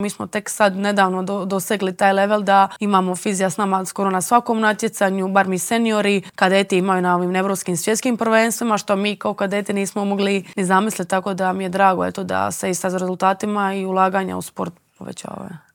U Intervjuu tjedna Media Servisa još je jednom proživjela tu čudesnu srijedu 31. srpnja 2024. i trenutke koji su ju doveli do vrha Olimpa.